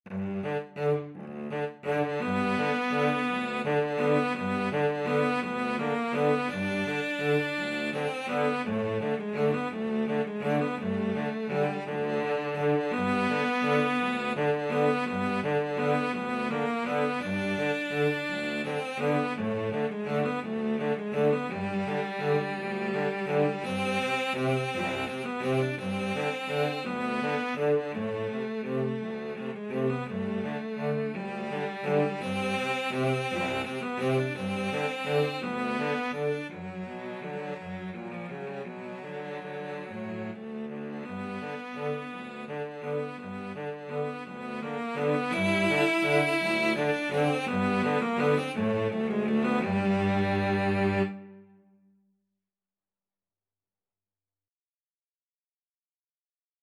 Tempo di valse =168
Classical (View more Classical Cello Duet Music)